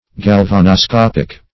Galvanoscopic \Gal*van`o*scop"ic\, a. Of or pertaining to a galvanoscope.
galvanoscopic.mp3